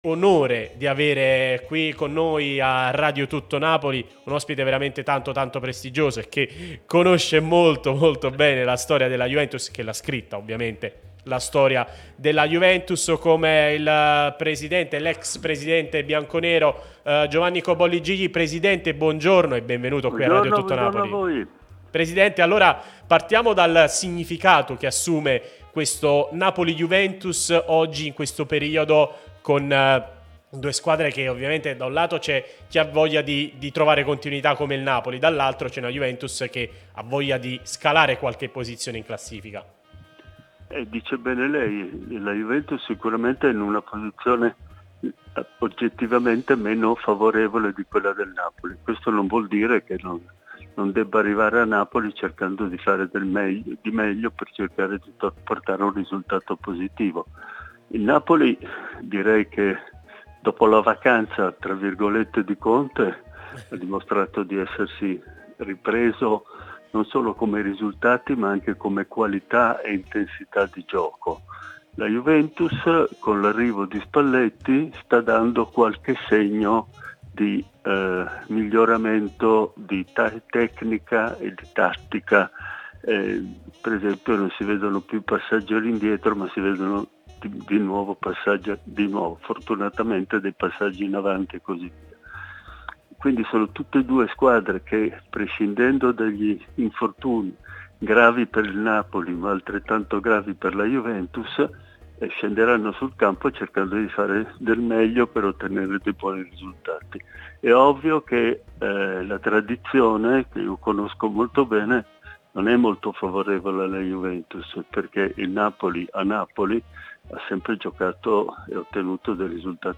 Giovanni Cobolli Gigli , ex presidente della Juventus, è intervenuto nel corso della trasmissione Il Bar di Tuttonapoli sulla nostra Radio Tutto Napoli , prima radio tematica sul Napoli, in onda tutto il giorno, che puoi ascoltare/vedere qui sul sito o sulle app ( qui per Iphone/Ipad o qui per Android ).